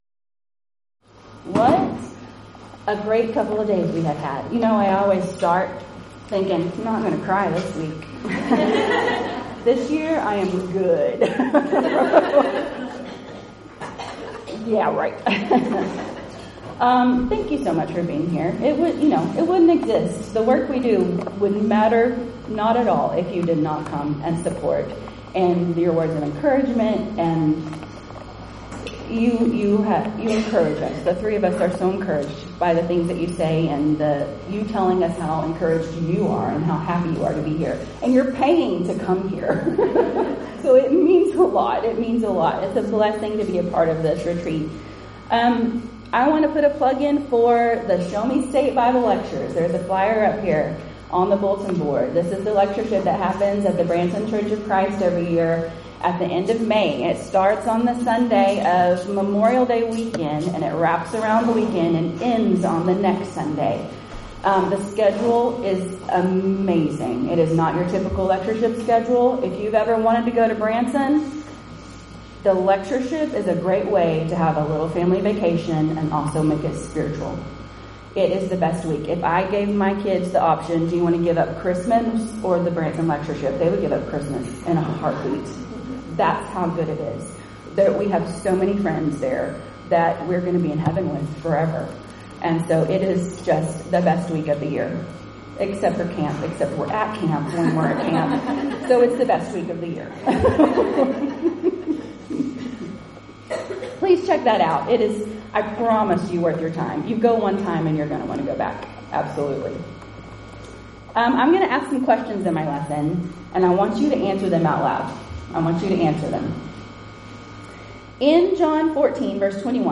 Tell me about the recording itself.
Event: 8th Annual Women of Valor Ladies Retreat